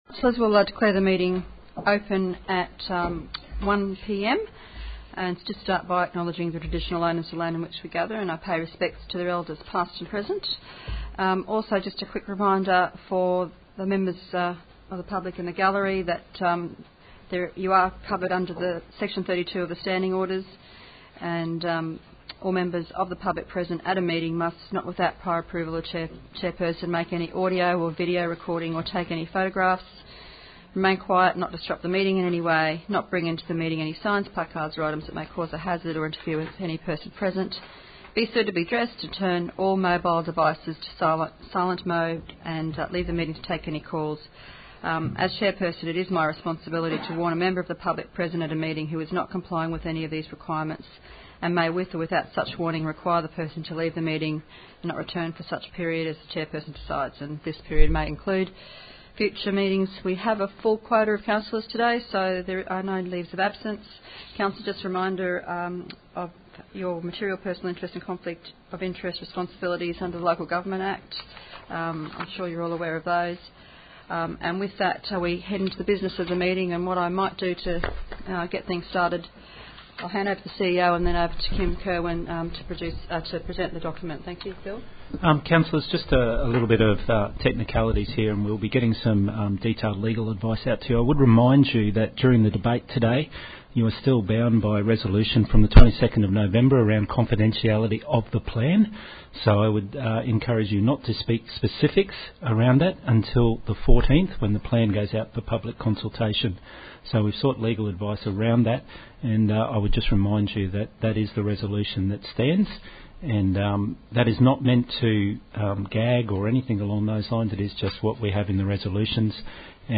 Special Meeting Audio